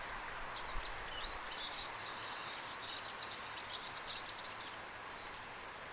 erikoisesti ääntelevä vihervarpunen
tuollainen_ehka_nuoren_vihervarpusen_aantely.mp3